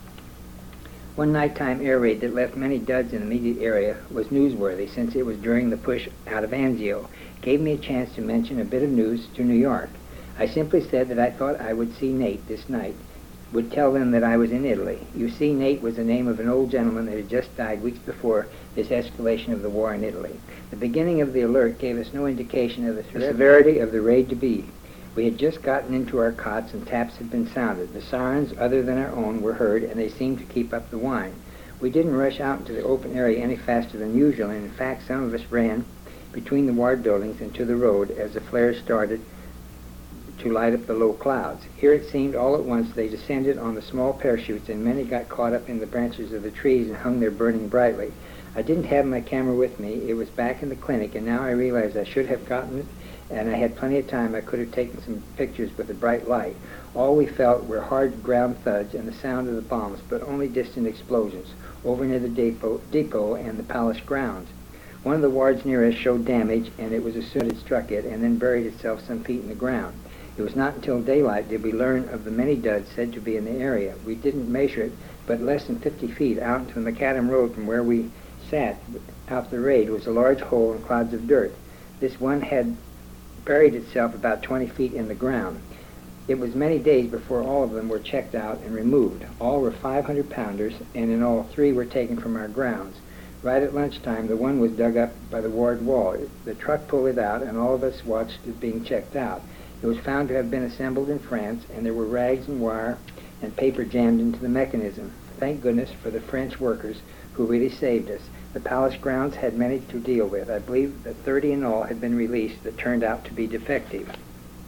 The recording was apparently made sometime in the 1980s (but before 1987).